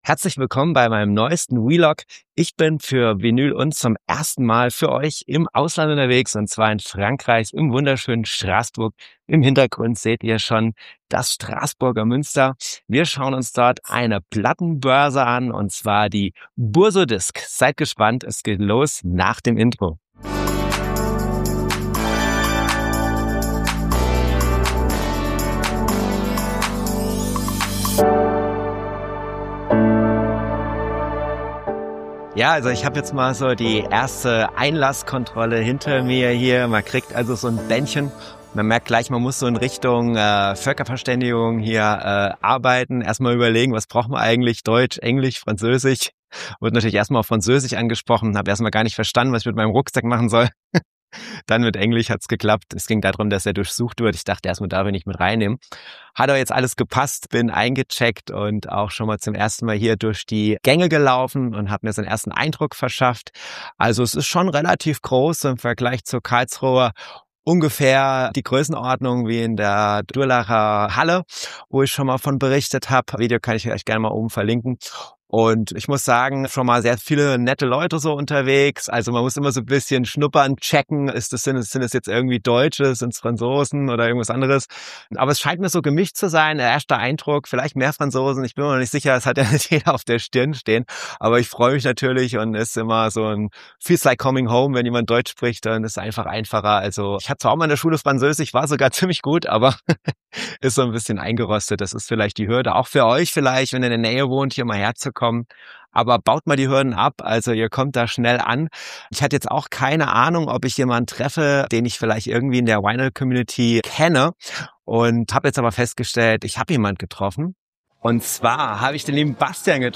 Sa., 18.04.2026, ab 20.15 Uhr Herzlich willkommen beim Vinyl & … Vlog von der Bourse aux disques de Strasbourg. Die Plattenbörse zählt zu den Highlights der Region für Plattenfans und hat eine lange Tradition.